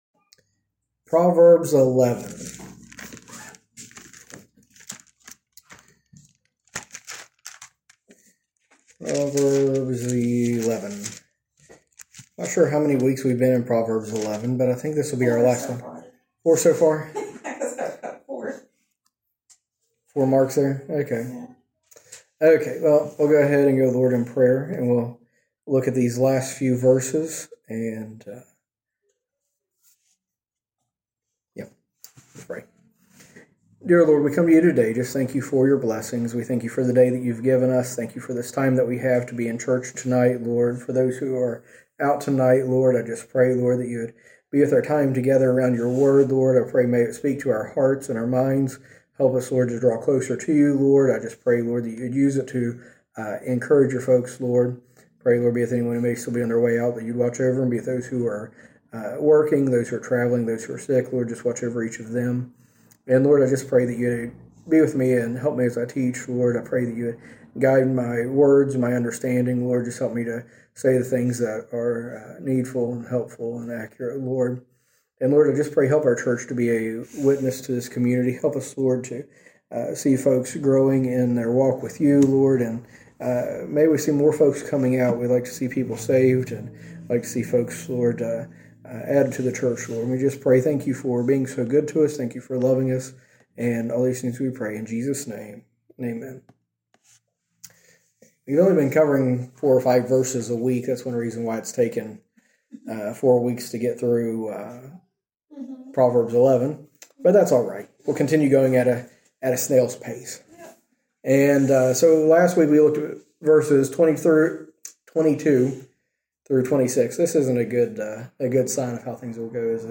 A message from the series "Proverbs."